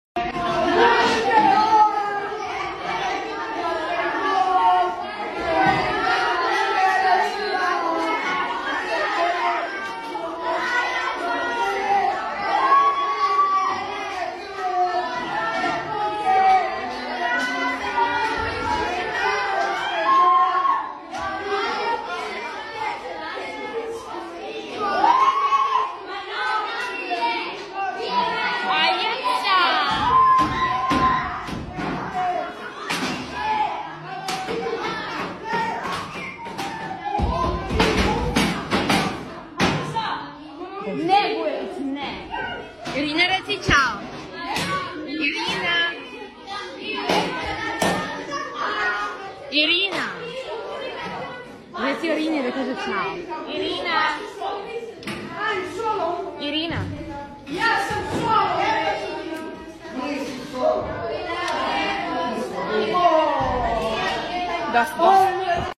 I got the original video of them while they were singing